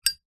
Звуки спичек, зажигалок
Быстрое открытие крышки металлической зажигалки Zippo